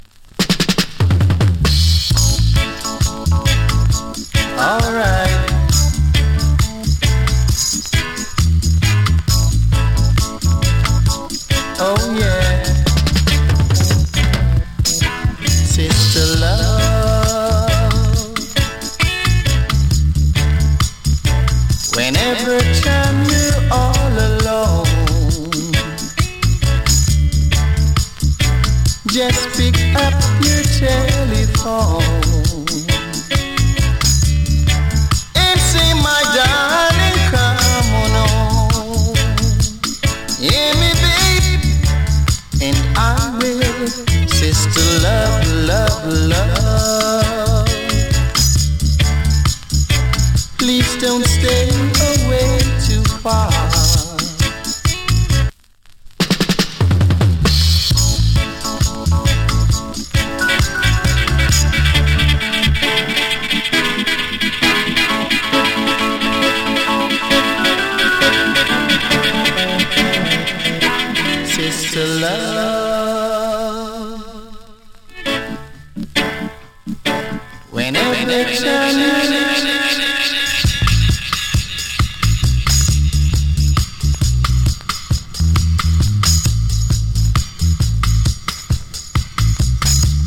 76年 NICE VOCAL REGGAE ＋ DUB.